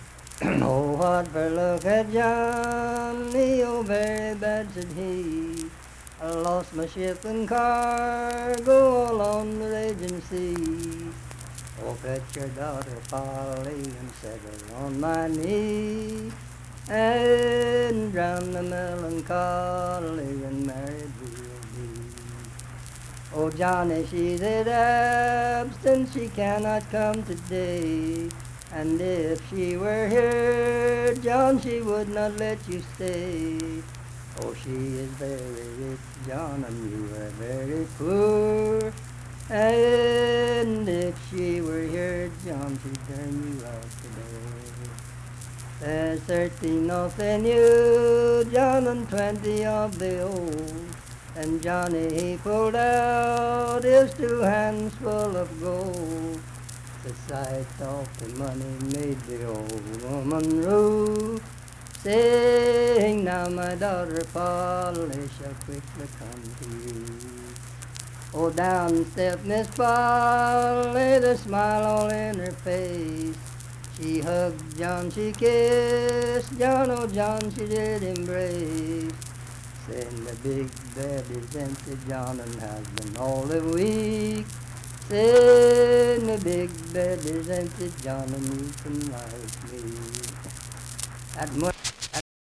Unaccompanied vocal music
Verse-refrain 4(8).
Voice (sung)
Harts (W. Va.), Lincoln County (W. Va.)